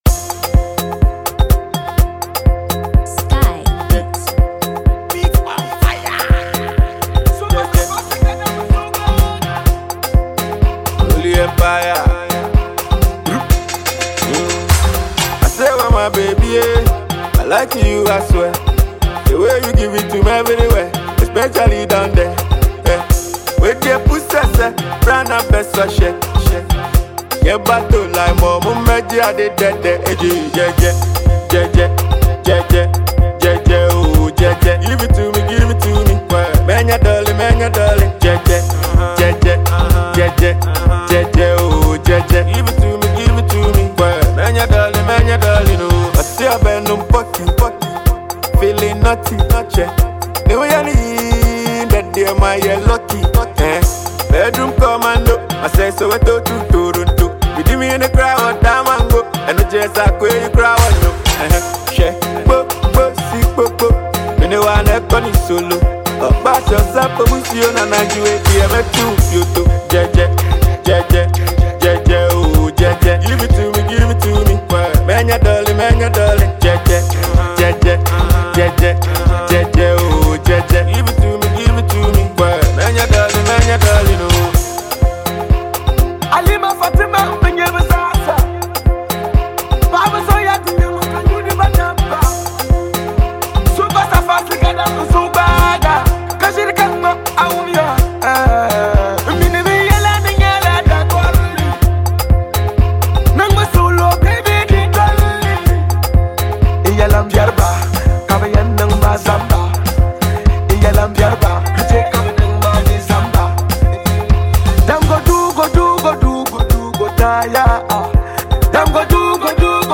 Ghana MusicMusic
Uber-talented singer and songwriter
catchy new single